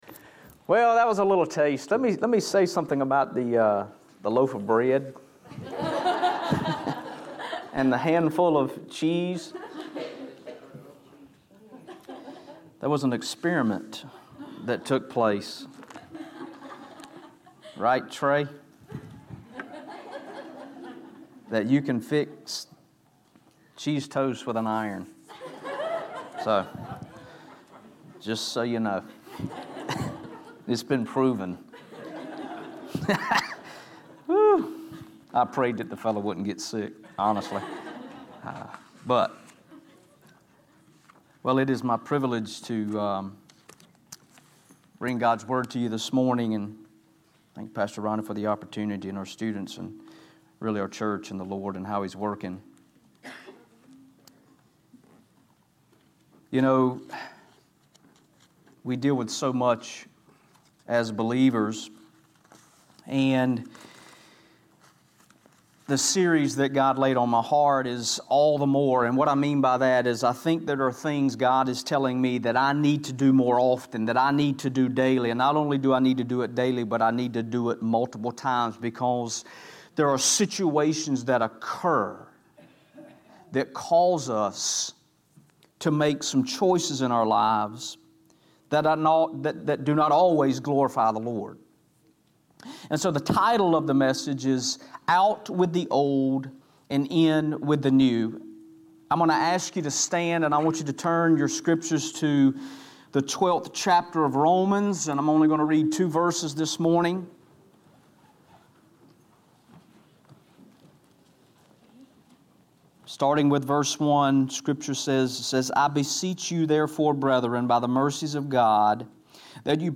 Sermons Archive - Page 13 of 16 - Gourd Springs Baptist Church